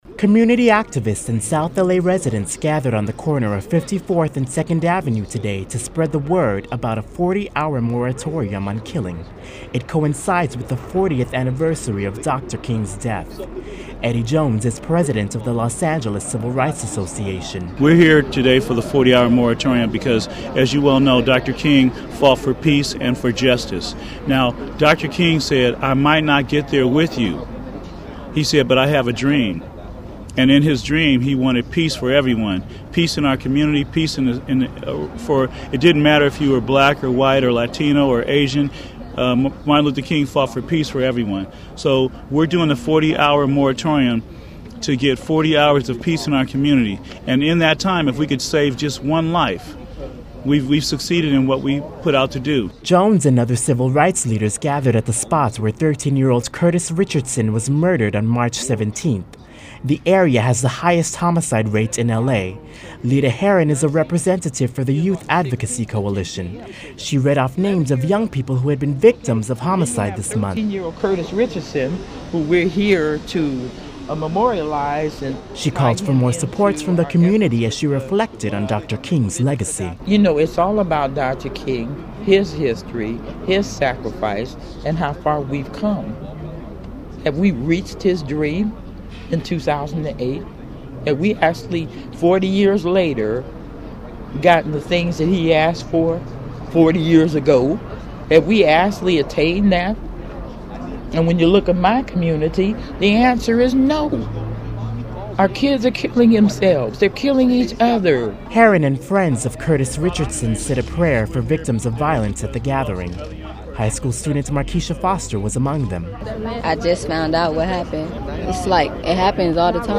In remembrance of Dr. Martin Luther King, Jr's assassination 40 years ago, civil rights leaders and South LA residents came together to spread the word about a 40-hour moratorium on killing. They gathered on a corner where a 13-year-old boy was recently killed and called for support from the community to honor King's dream for peace.